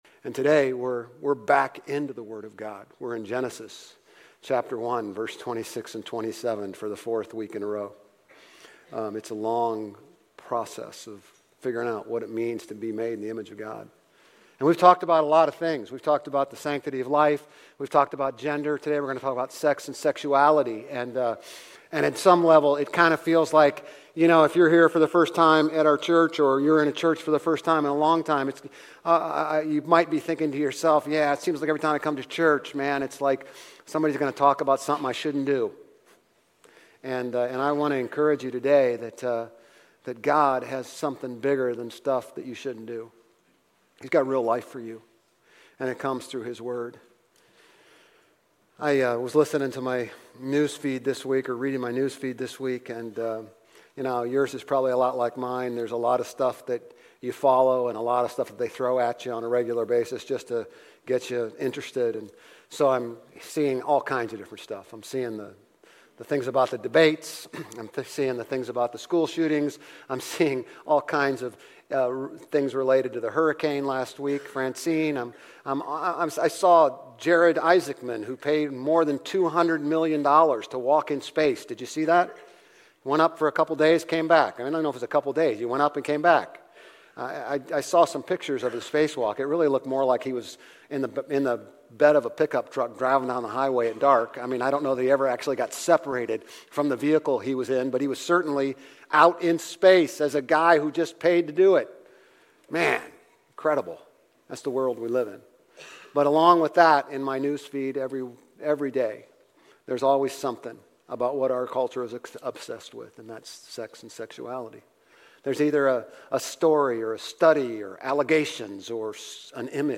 Grace Community Church Old Jacksonville Campus Sermons Genesis 1:26-28, 2:21-25, Rom 1:29-30 - Sexuality Sep 15 2024 | 00:32:19 1x 00:00 / 00:32:19 Subscribe Share RSS Feed Share Link Embed